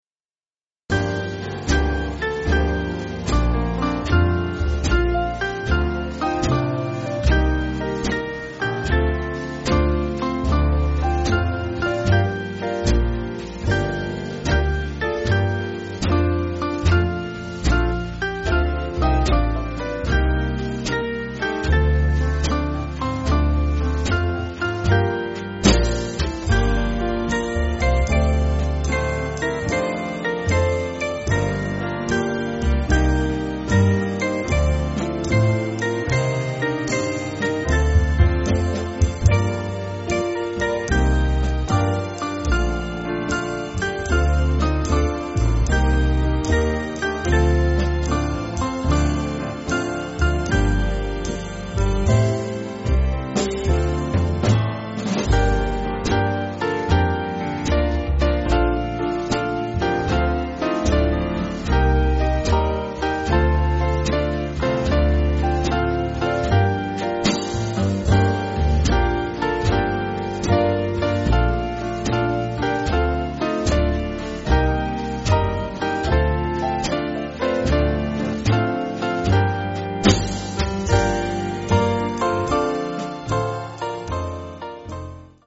Swing Band
(CM)   5/Gm-Am